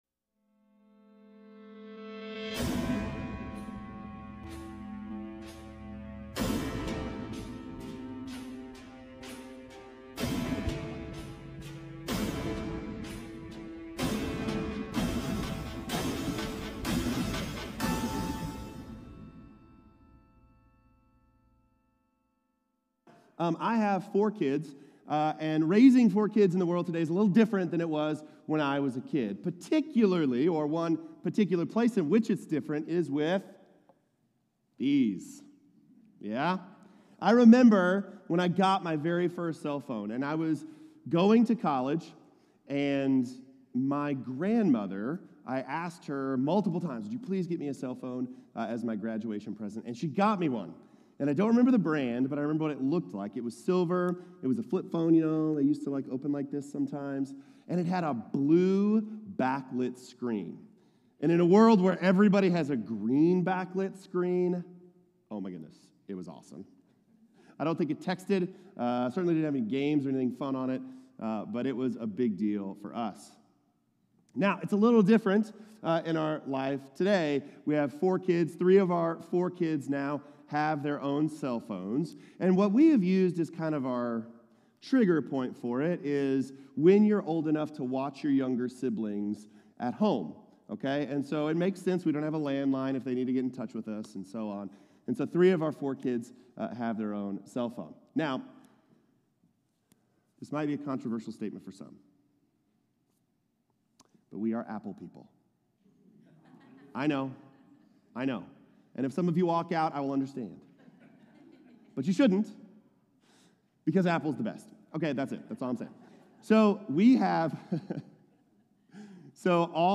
We-Are-Renewed-Sermon-3.16.25.m4a